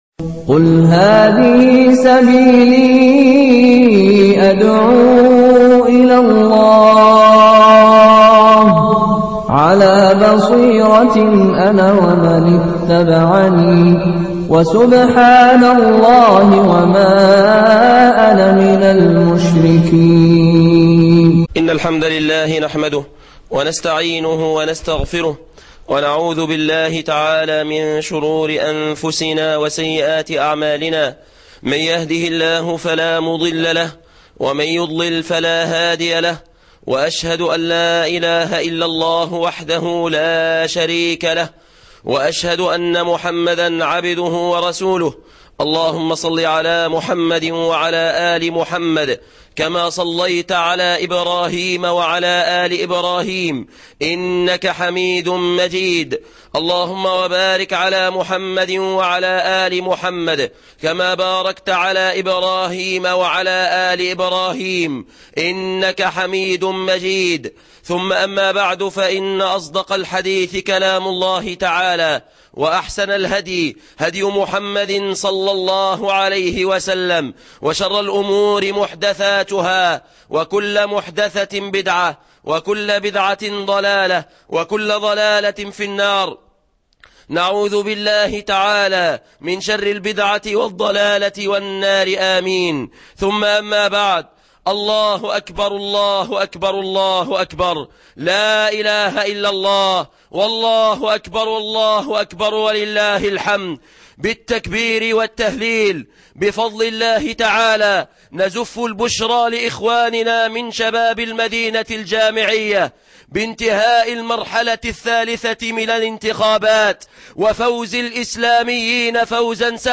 بركات تطبيق الشريعة - خطبة جمعة بمسجد المدينة الجامعية بجامعة المنصورة